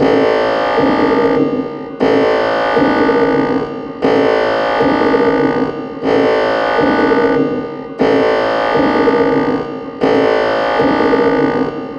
futuristic-alarm-glued.wav
Alarms